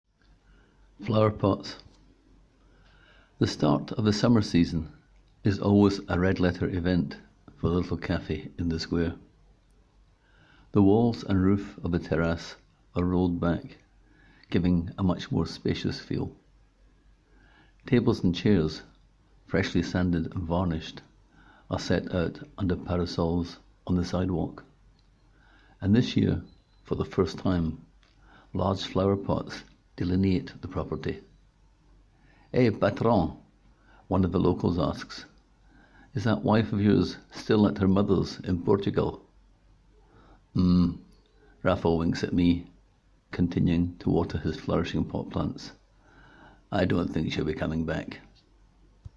Click here to hear the writer read his words: